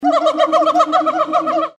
• Качество: высокое
Звук колобуса